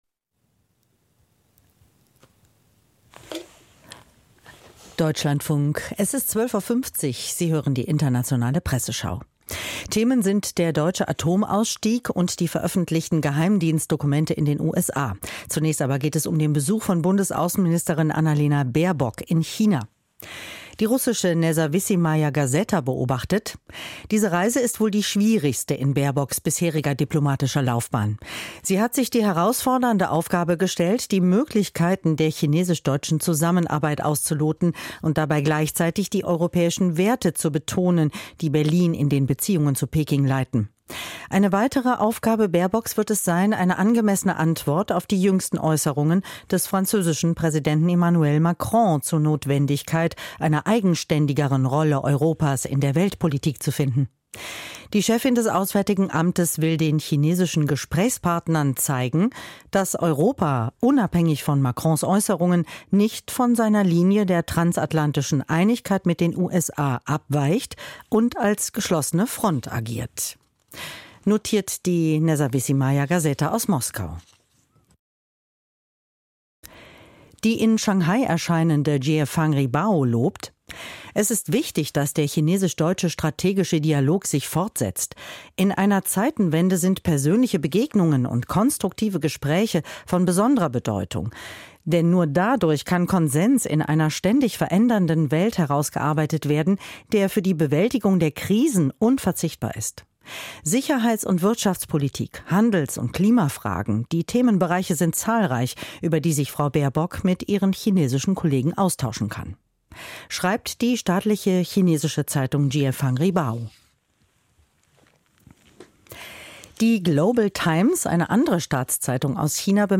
Die internationale Presseschau